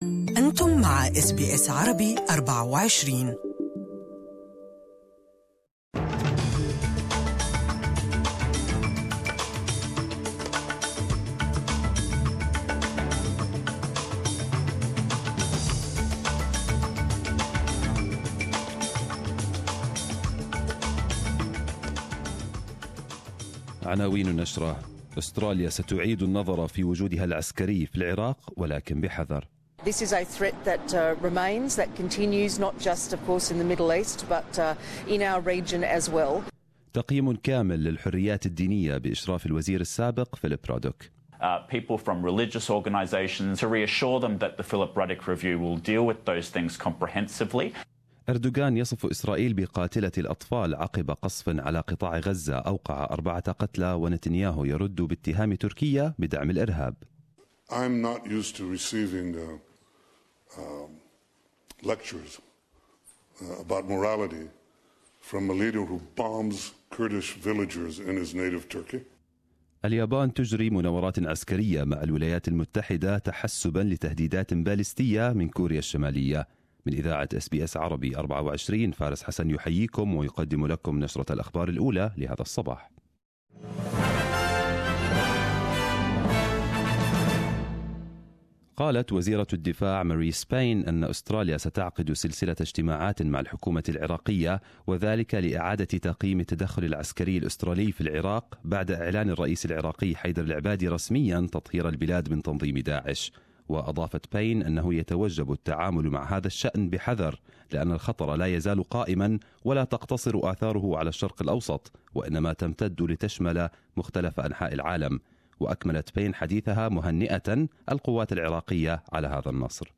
Arabic News Bulletin 11/12/2017